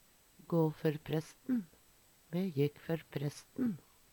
gå før presten - Numedalsmål (en-US)